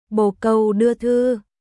bồ câu đưa thư伝書鳩ボー カウ ドゥア トゥー